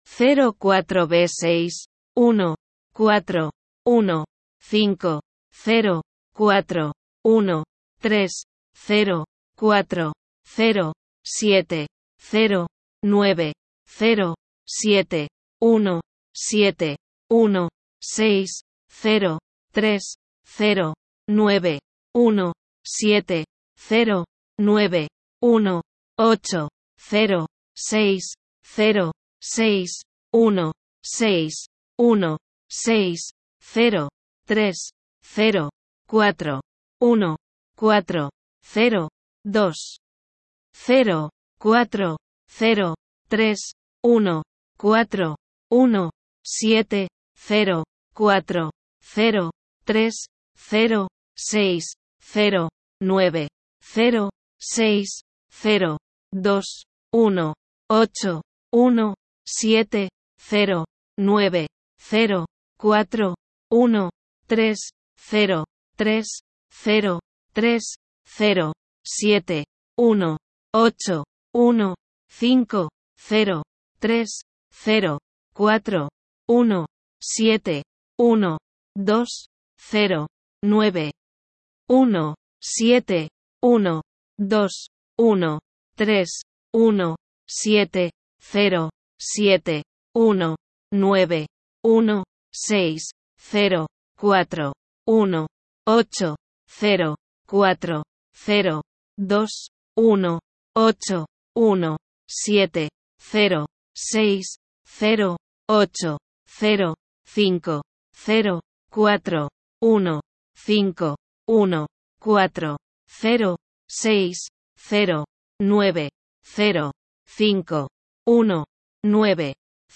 Number's Station
“Testing, testing, one, two, one, zero..” - the bunnies found a strange radio station when looking for uplifting BunnyBop; can you find out what the nice Spanish lady is saying?